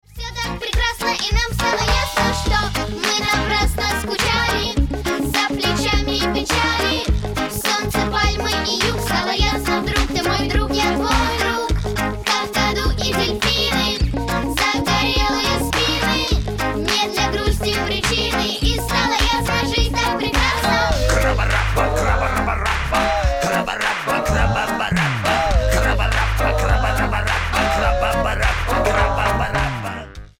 Позитивная детская песенка